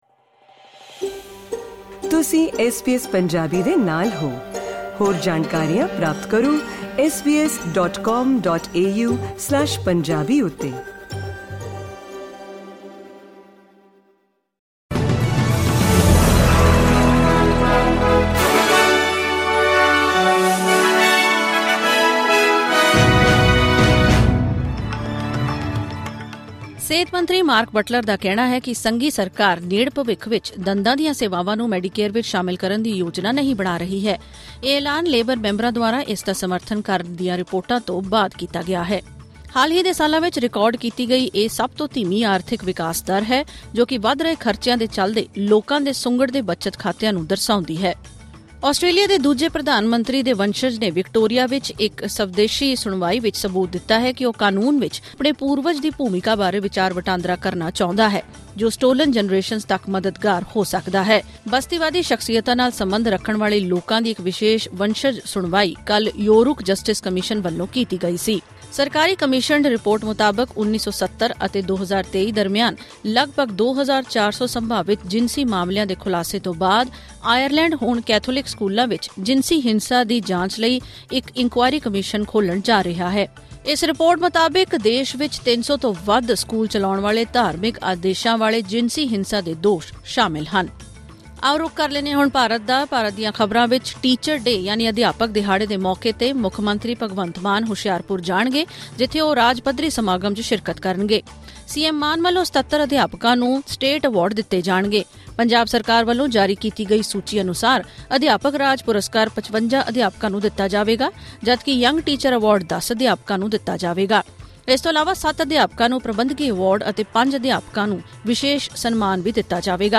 ਐਸ ਬੀ ਐਸ ਪੰਜਾਬੀ ਤੋਂ ਆਸਟ੍ਰੇਲੀਆ ਦੀਆਂ ਮੁੱਖ ਖ਼ਬਰਾਂ: 5 ਸਤੰਬਰ 2024